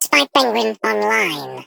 Sfx_tool_spypenguin_vo_rebuilt_06.ogg